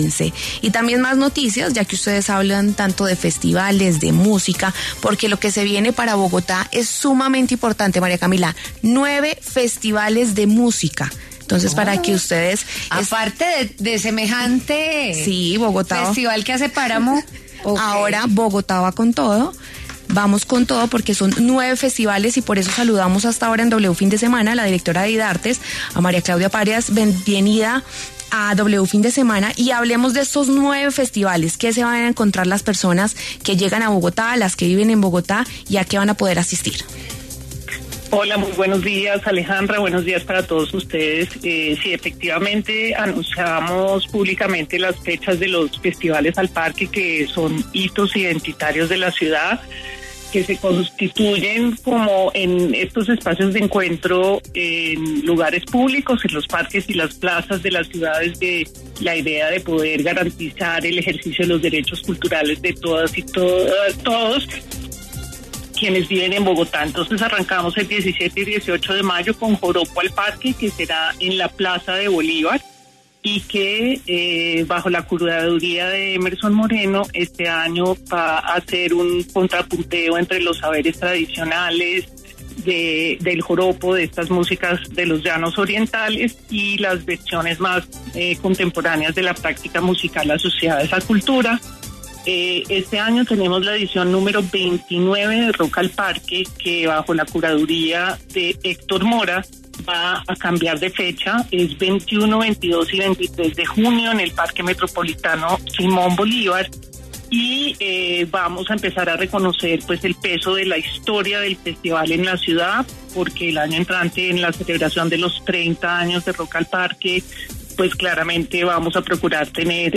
La directora de Idartes, María Claudia Parias, llegó a los micrófonos de W Fin de Semana para hablar de algunos detalles de los ocho Festivales al Parque 2025.